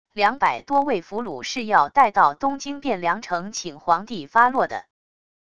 两百多位俘虏是要带到东京汴梁城请皇帝发落的wav音频生成系统WAV Audio Player